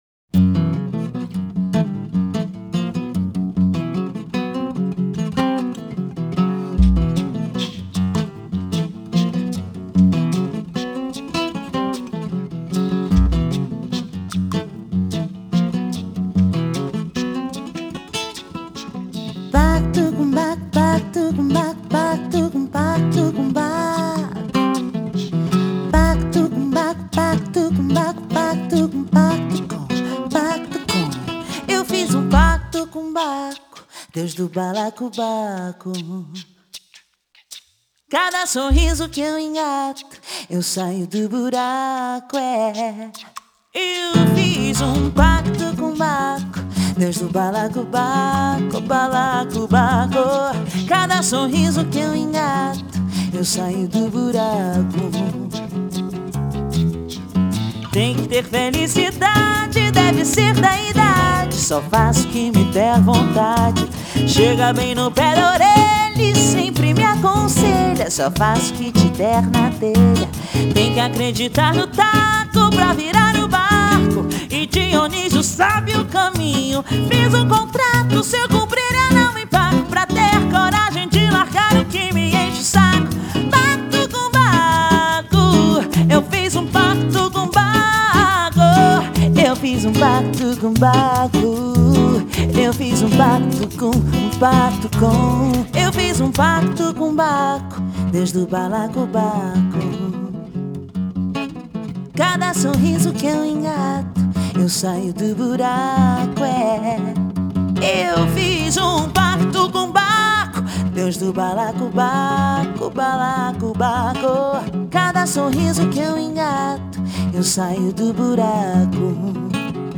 Genre: Blues, Vocals